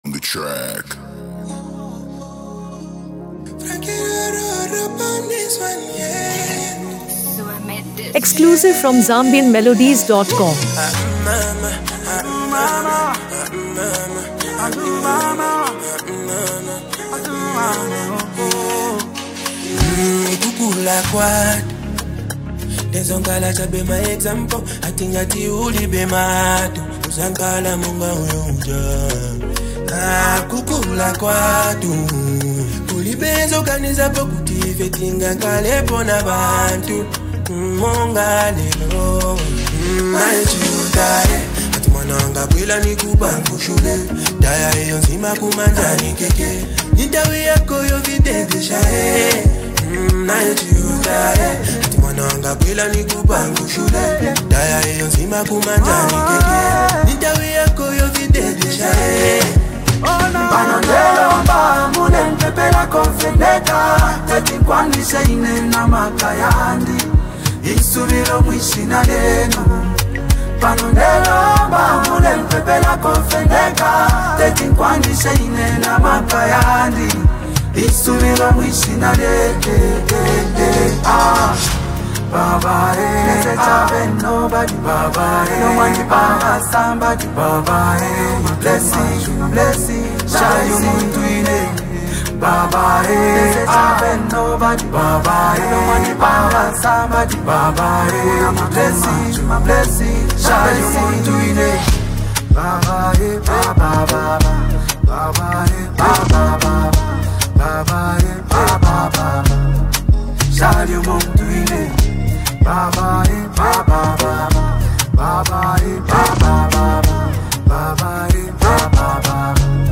Zambian Music
Known for his soulful delivery and meaningful lyrics
melodic vocals